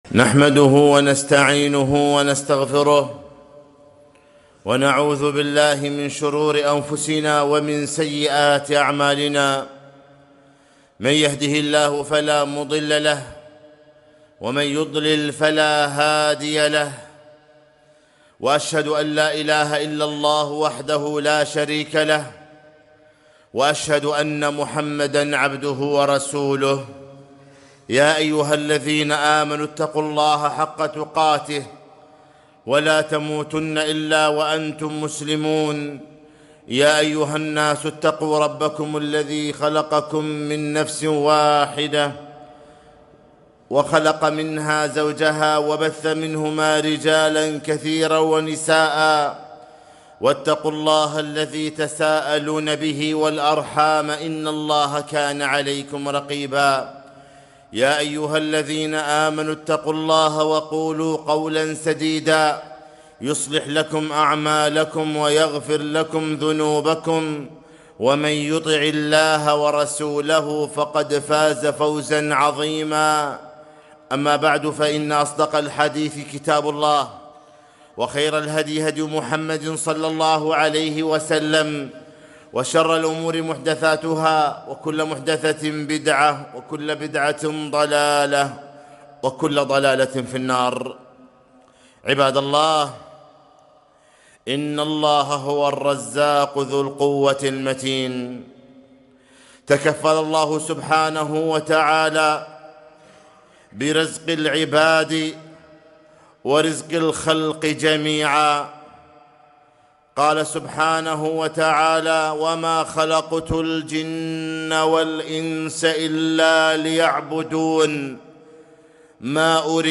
خطبة - لاتقلق فالرزق بيد الله